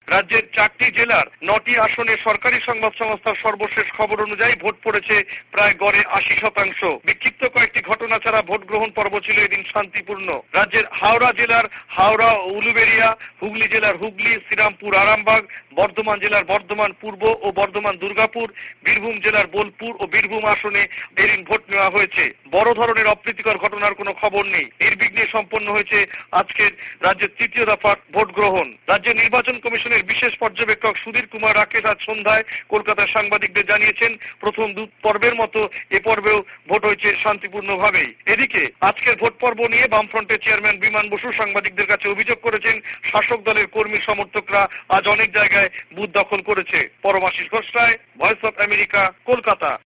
কোলকাতা সংবাদদাতাদের প্রতিবেদন